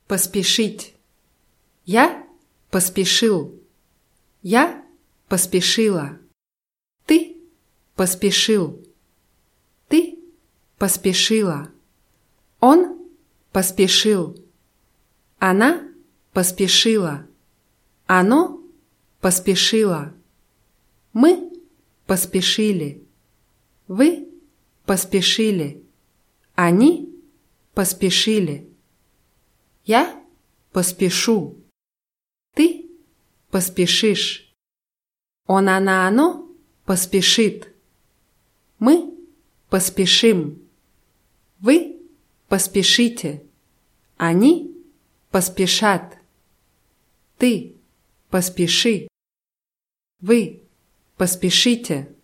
поспешить [paßpʲischýtʲ]